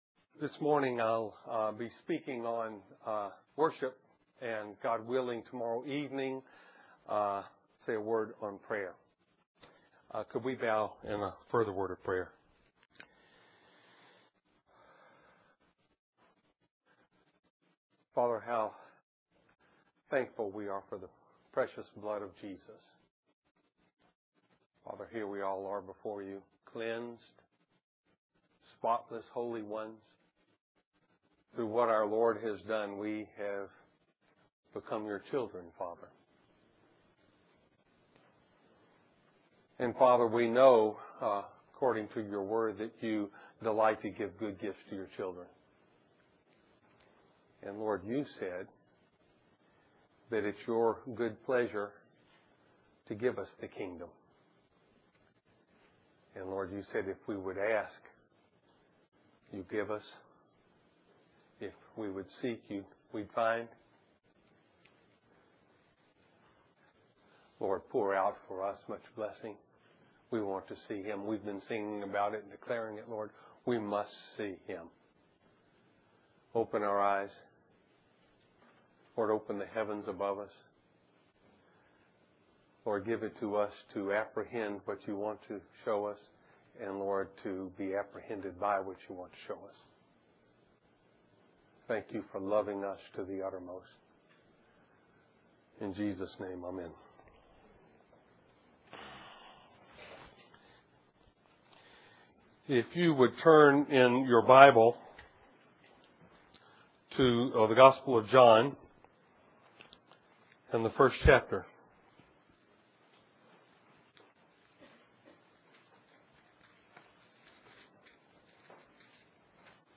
A collection of Christ focused messages published by the Christian Testimony Ministry in Richmond, VA.
Winter Youth Conference